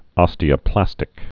(ŏstē-ə-plăstĭk)